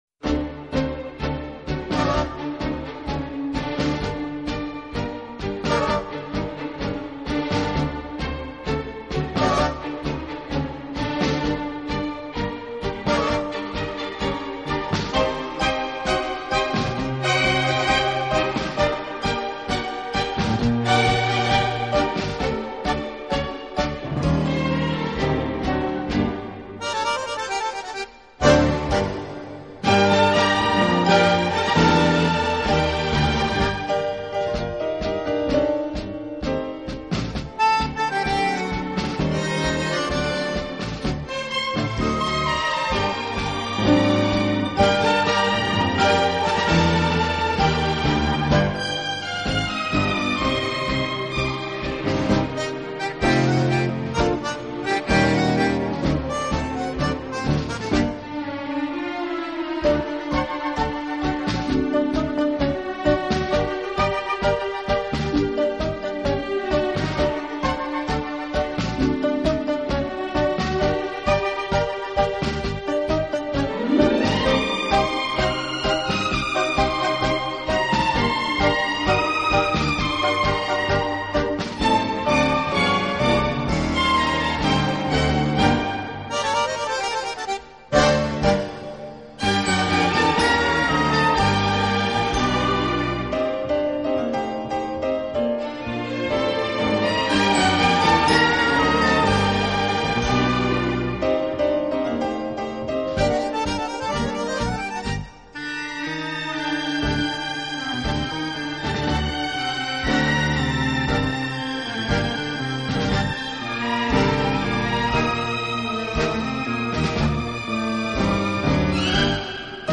【探戈】
乐背景的不同，以各种乐器恰到好处的组合，达到既大气有力又尽显浪漫的效果。
乐队的弦乐柔和、优美，极有特色，打击乐则气度不凡，而手风琴、钢琴等乐器